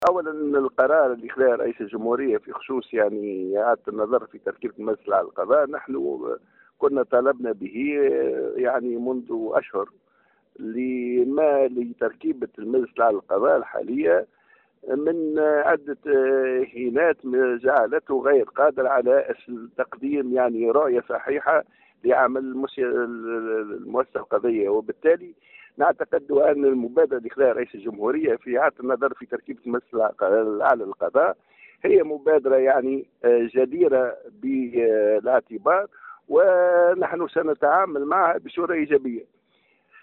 اعتبر عميد المحامين إبراهيم بودربالة صباح اليوم في تصريح ل ‘ام اف ام ‘ أنه لم يتم إلغاء المجلس الأعلى للقضاء وإنما إعادة النظر في تركيبته وهو ما طالبوا به منذ عدة أشهر مؤكدا تعامله بإيجابية مع قرار سعيّد ومساندة لكل ما يستوجب التقييم والإصلاح.